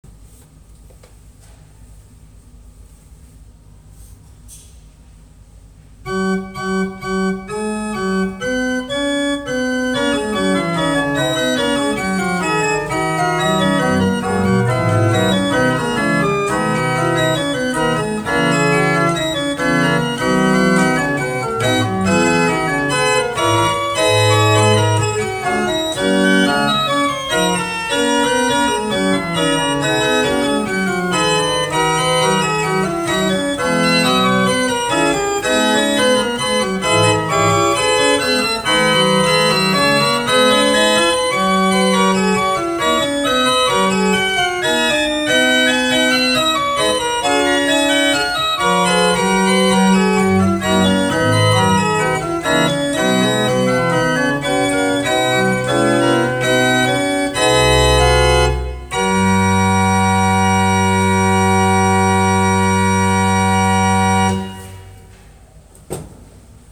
Ore 16.00, Chiesa di Santa Cristina, piazza San Carlo, Torino.
Lezione concerto